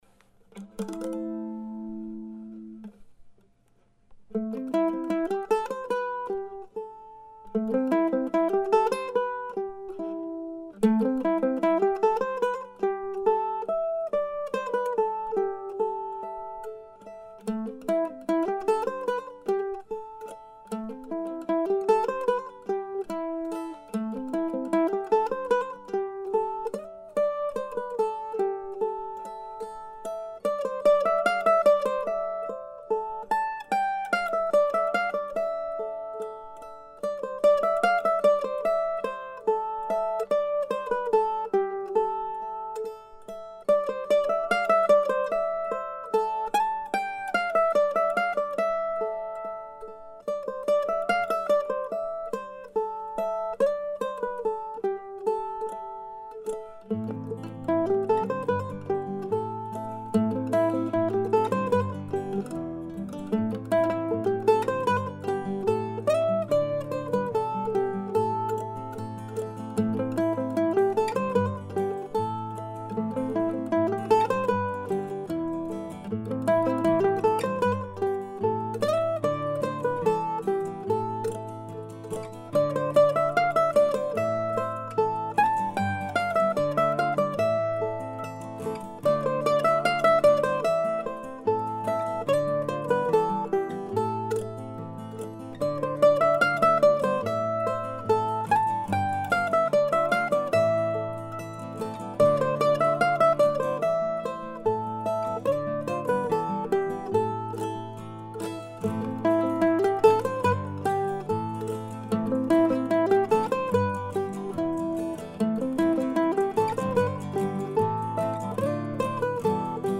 Still one of my favorite tunes and I really like this simple recording.